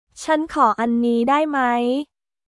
チャン　コー　アンニー　ダイ　マイ　カップ/カー